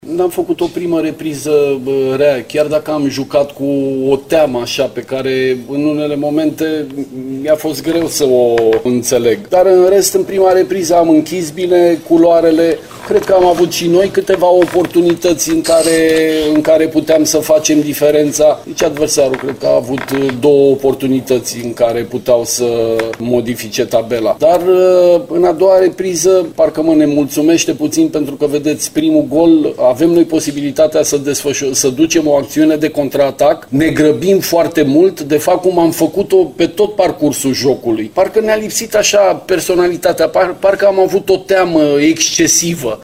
Selecționerul Emil Săndoi spune că elevii săi au jucat aseară cu o teamă nejustificată: